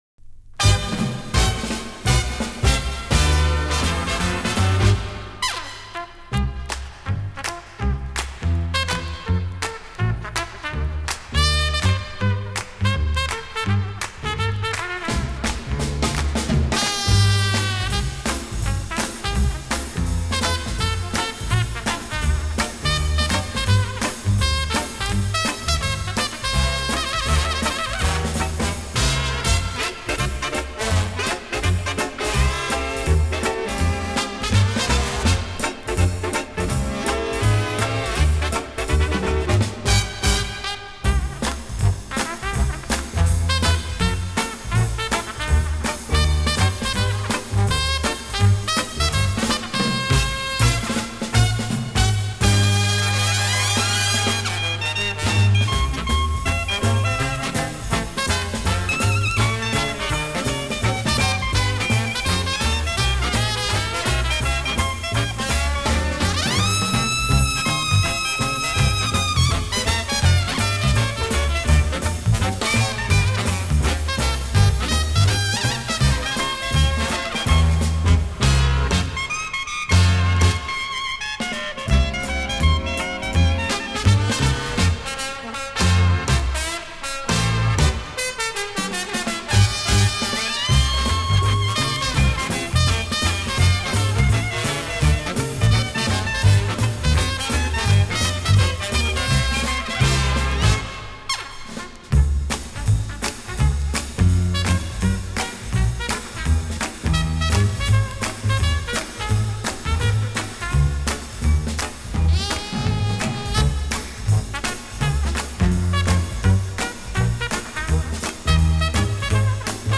Genre:Easy Lising